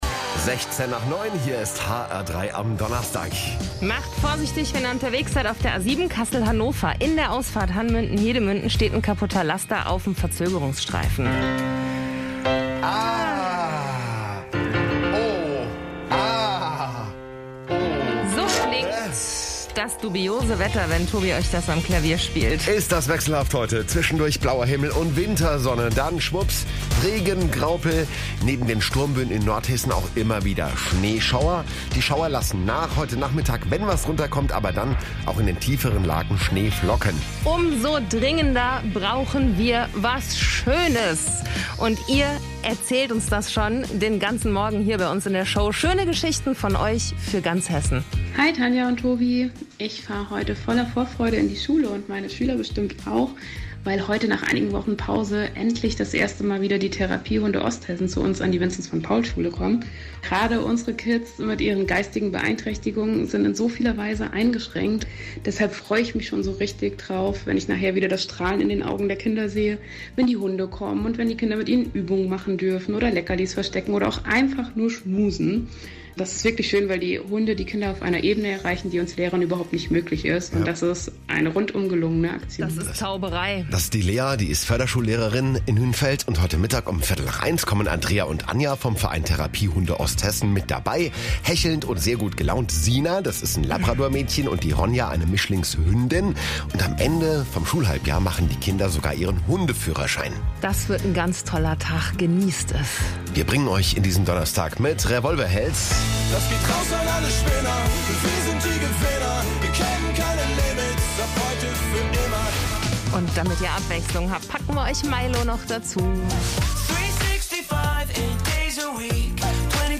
21.01.2022 HR3 Radio Mitschnitte
hr3 Mitschnitt Therapiehunde Do & Fr.mp3